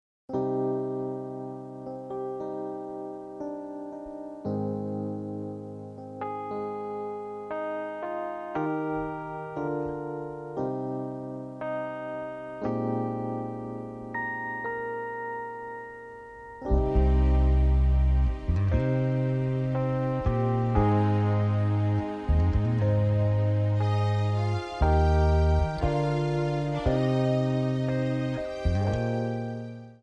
Eb
Backing track Karaoke
Pop, Disco, 1970s